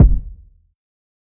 TC2 Kicks21.wav